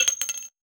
weapon_ammo_drop_07.wav